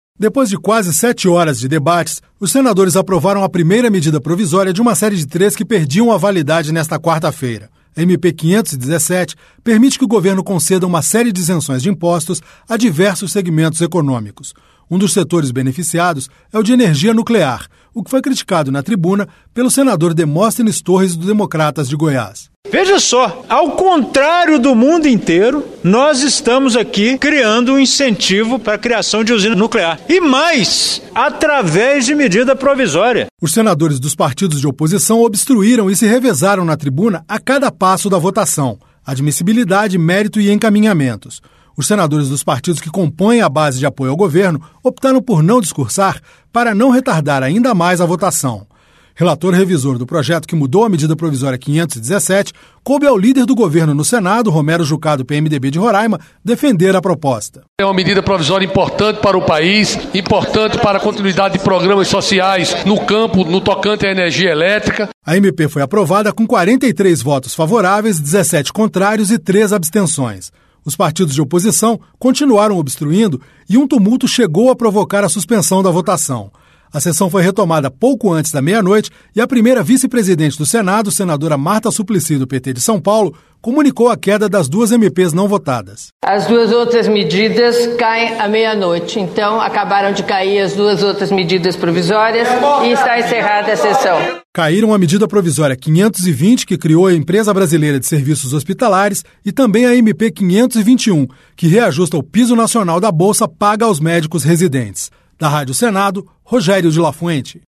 Um dos setores beneficiados é o de energia nuclear, o que foi criticado na tribuna pelo senador Demóstenes Torres, do Democratas de Goiás.